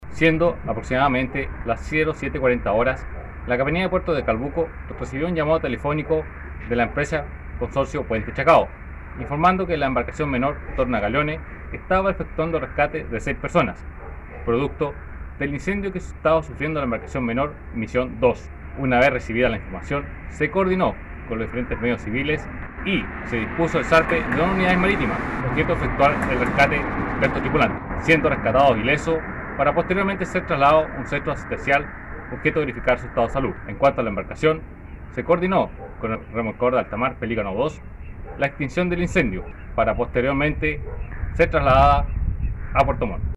El hecho ocurrió el día sábado 19 de diciembre en los alrededores del área marítima del proyecto Puente Chacao, donde se encontraba este bote pesquero fondeado, de acuerdo a lo relatado por Jonathan Dimter, capitán de Puerto de Calbuco.